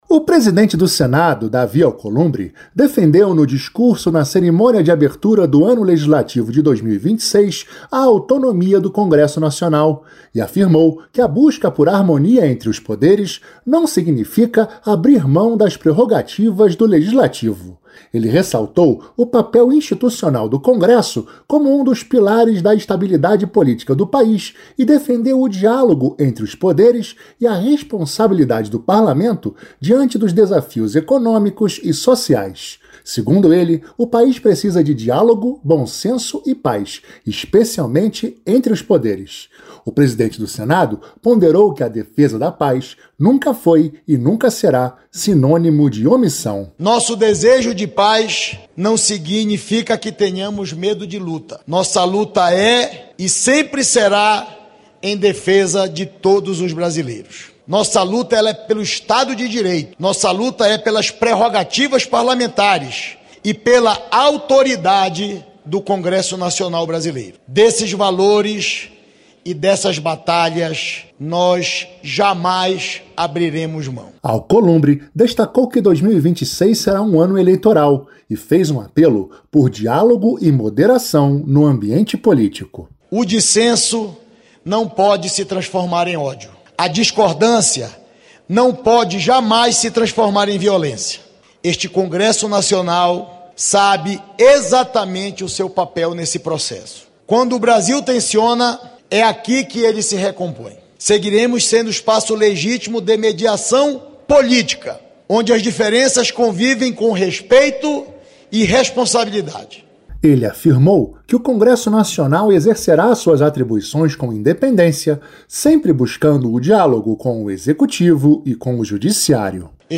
O presidente do Congressso, Davi Alcolumbre, defendeu, no discurso de abertura do ano legislativo de 2026, na segunda-feira (2), a autonomia do Congresso Nacional e afirmou que a busca por harmonia entre os Poderes não significa abrir mão das prerrogativas do Legislativo. Ele ressaltou o papel institucional do Congresso como um dos pilares da estabilidade política do país e defendeu o diálogo entre os Poderes e a responsabilidade do Parlamento diante dos desafios econômicos e sociais.